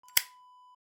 Zippo Close Wav Sound Effect
Description: The sound of a zippo lighter cap closing
Properties: 48.000 kHz 24-bit Stereo
A beep sound is embedded in the audio preview file but it is not present in the high resolution downloadable wav file.
Keywords: zippo, cigarette, lighter, cap, click, shut, close, closing
zippo-close-preview-1.mp3